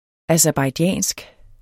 aserbajdsjansk adjektiv Bøjning -, -e Udtale [ asæɐ̯bɑjˈdjæˀnsg ] Betydninger fra Aserbajdsjan; vedr.